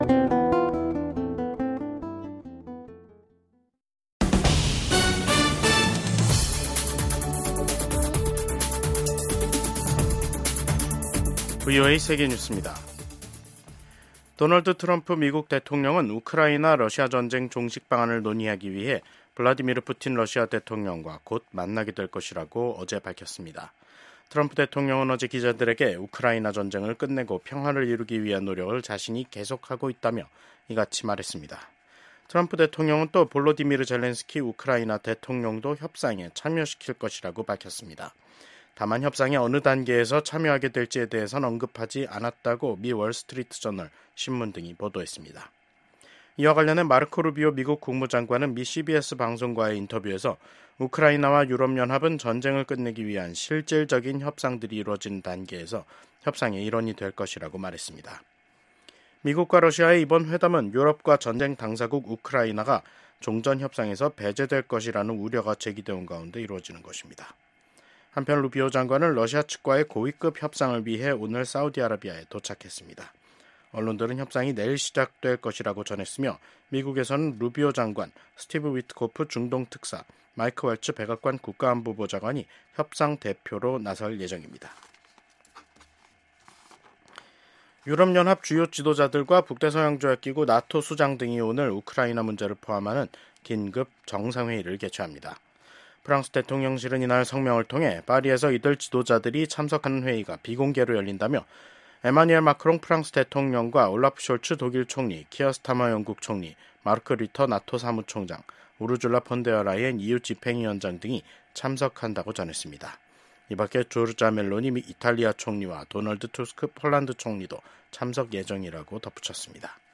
VOA 한국어 간판 뉴스 프로그램 '뉴스 투데이', 2025년 2월 17일 2부 방송입니다. 미국과 한국, 일본의 외교장관들이 북한의 완전한 비핵화에 대한 확고한 의지를 재확인했습니다. 15일 열린 미한일 외교장관 회의에서 북한의 완전한 비핵화 목표를 확인한 데 대해 도널드 트럼프 행정부가 투트랙 대북전략을 구사할 것이라는 관측이 나왔습니다. 미국의 전술핵무기를 한반도에 배치할 경우 위기 상황에서 생존 가능성이 낮다고 전 미국 국방부 차관보가 밝혔습니다.